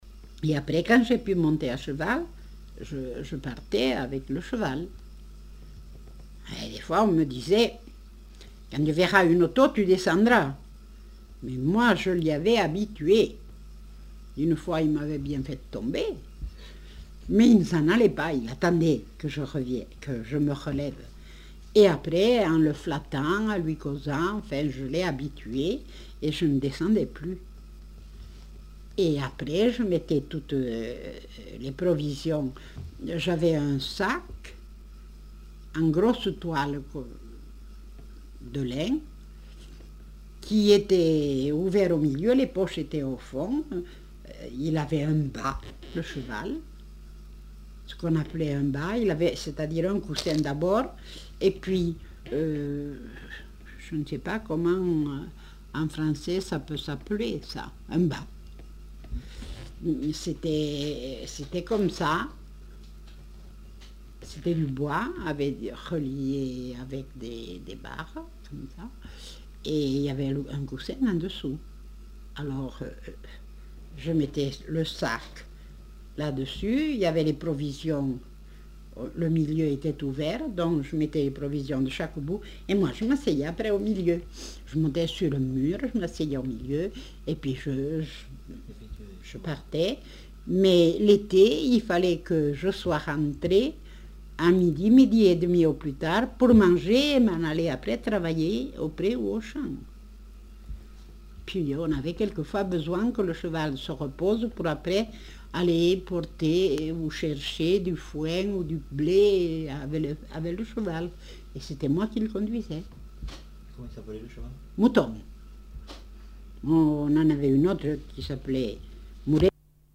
Aire culturelle : Comminges
Genre : récit de vie